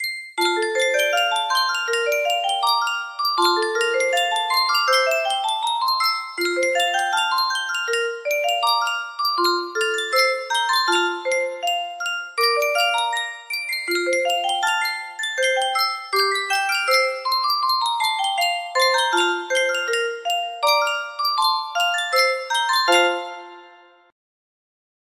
Sankyo 23 Note Music Box - When You and I Were Young, Maggie AXR
Full range 60